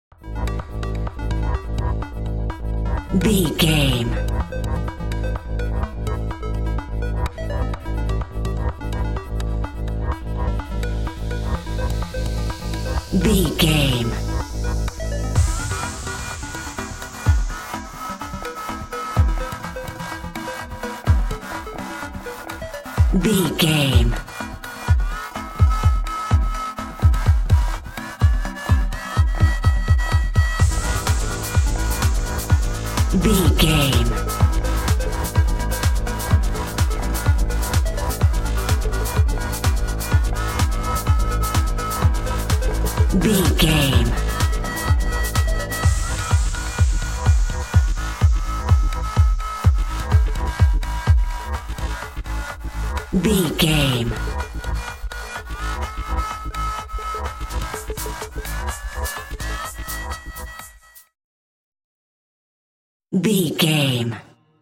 Top 40 Electronic Dance Music 60 Sec.
Aeolian/Minor
G#
Fast
energetic
uplifting
hypnotic
industrial
groovy
drum machine
synthesiser
house
techno
trance
synth leads
synth bass
upbeat